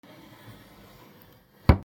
戸を閉める NR
/ K｜フォーリー(開閉) / K05 ｜ドア(扉)
『シューパタン』